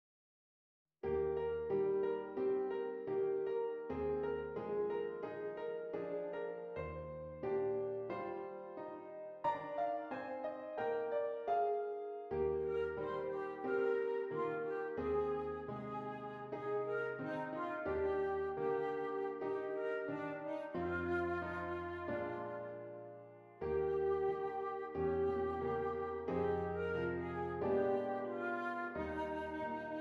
Flute Solo with Piano Accompaniment
Does Not Contain Lyrics
E Flat Major
Moderately